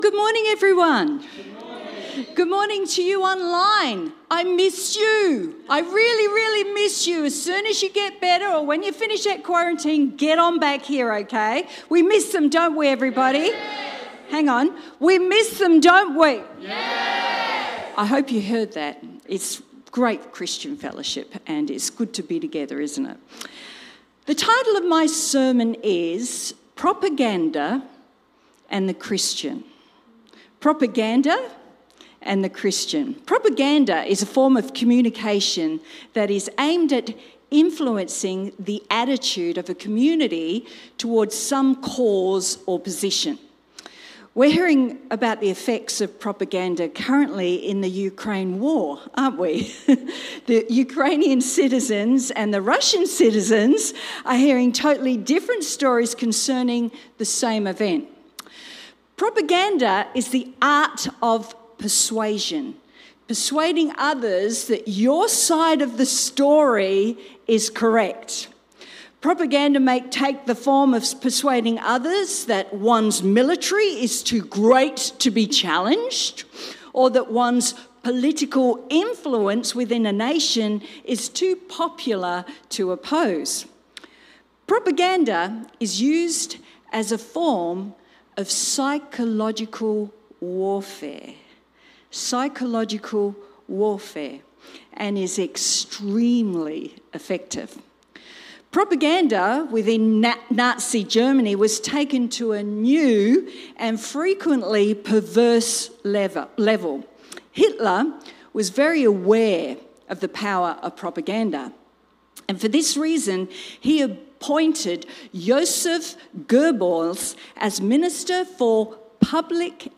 This sermon was preached on 20th March 2022.